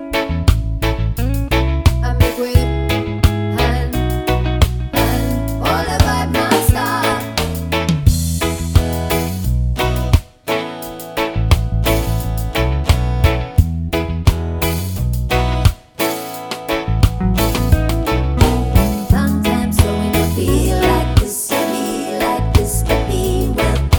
no Backing Vocals Reggae 2:55 Buy £1.50